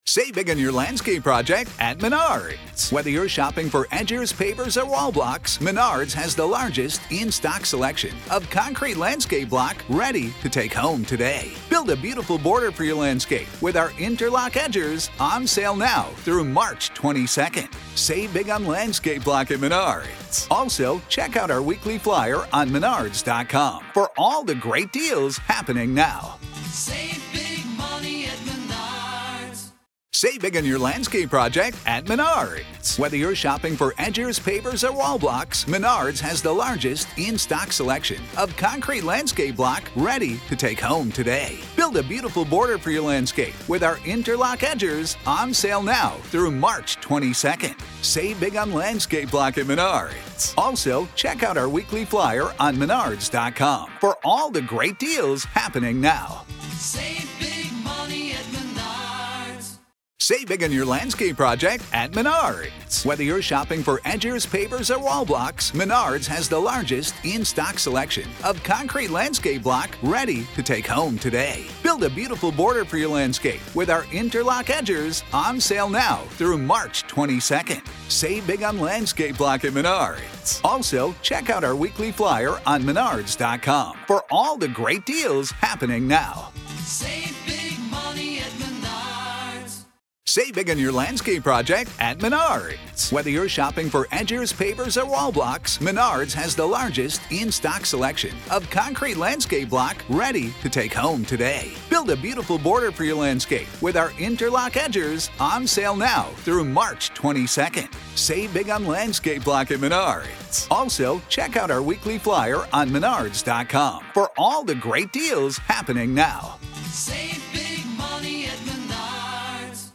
Call in. We're going live.